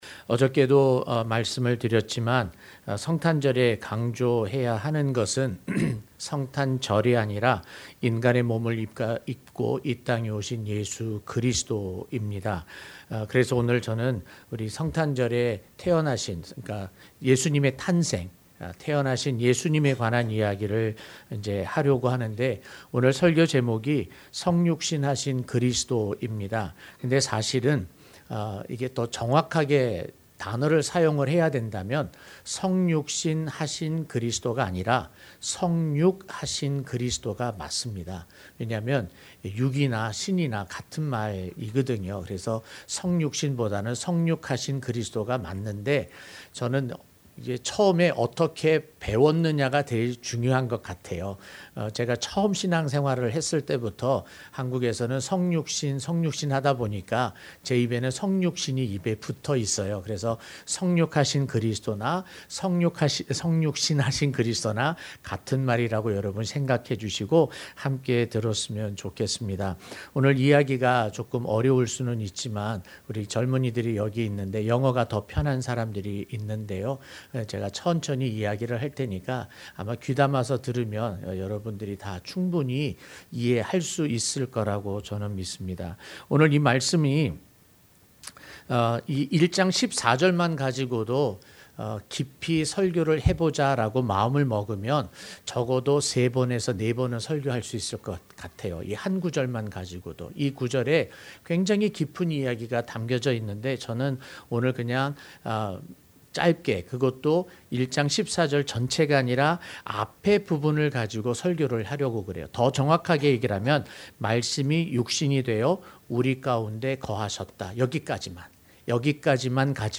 성육신하신 그리스도(성탄절예배)